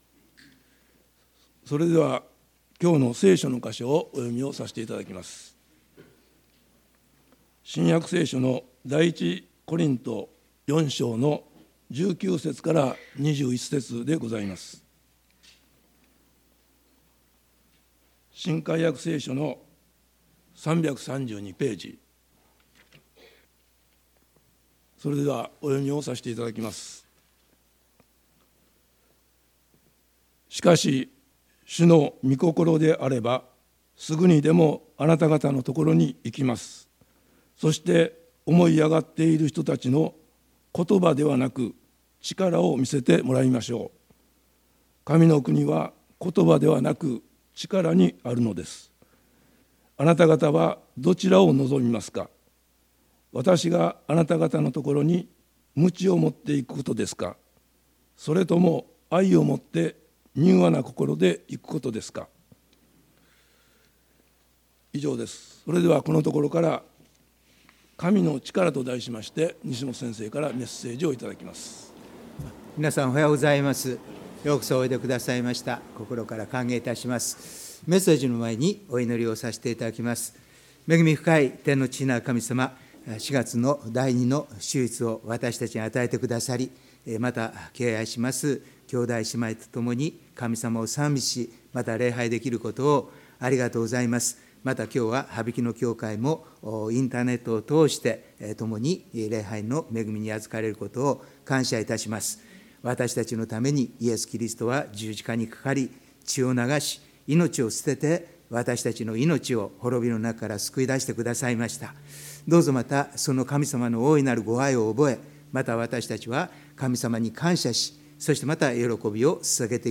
メッセージ概要 教会とはキリストによって招集された者の集まりですが、人間には神から離れ、自己の正しさを主張し、高ぶる弱さがあります。